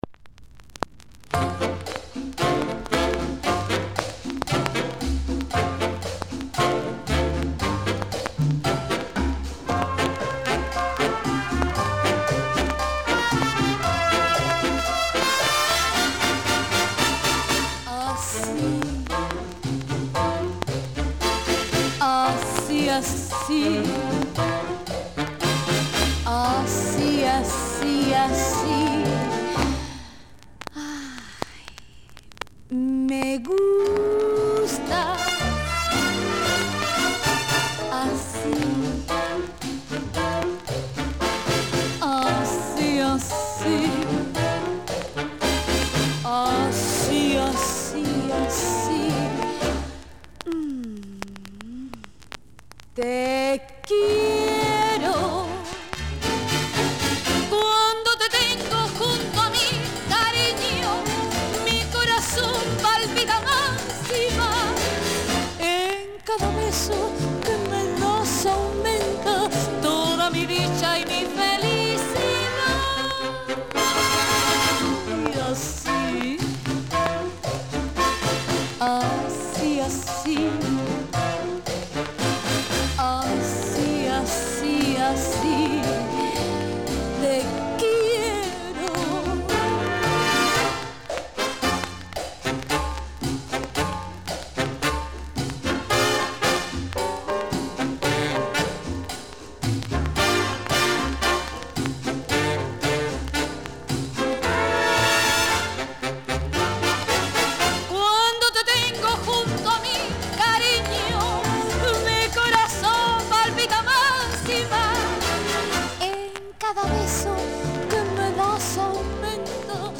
キューバ録音!!!